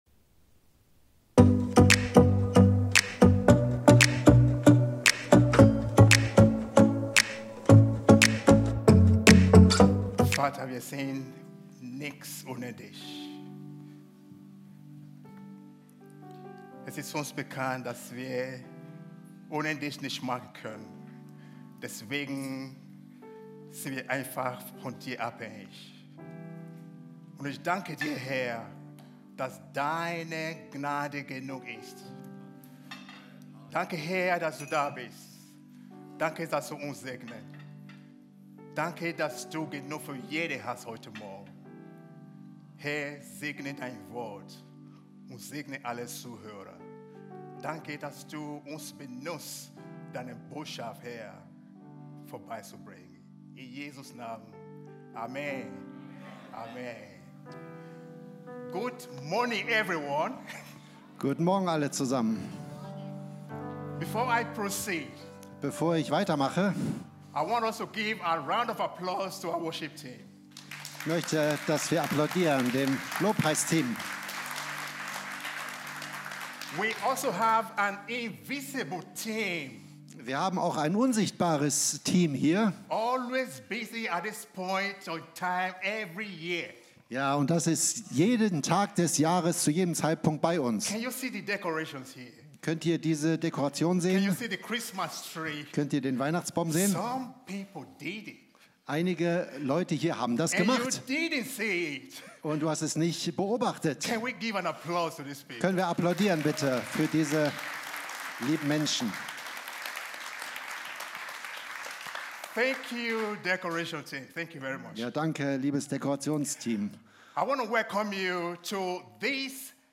Video und MP3 Predigten
Kategorie: Sonntaggottesdienst Predigtserie: Advent - eine Ankunft, die verwandelt